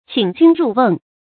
qǐng jūn rù wèng
请君入瓮发音
成语正音瓮，不能读作“wēnɡ”。